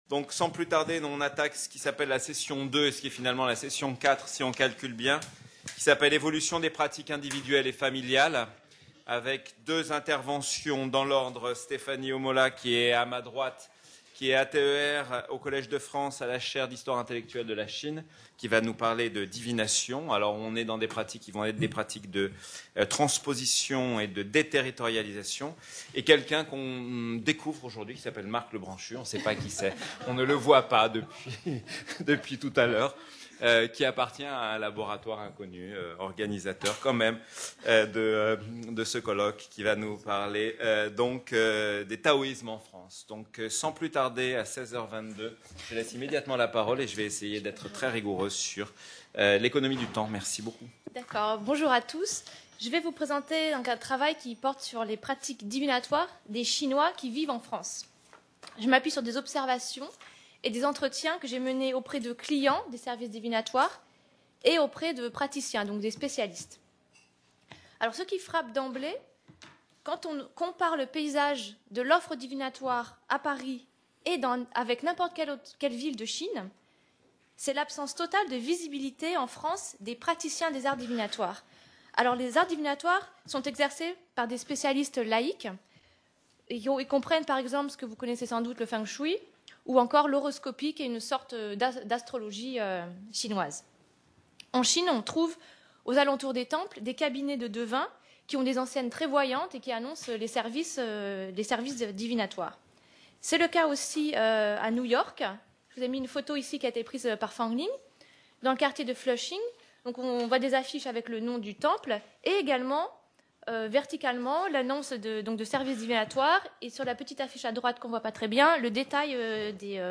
Colloque international du 18 au 20 mai 2016 au CNRS site Pouchet, Paris 17e et à l'INALCO, Paris 13e.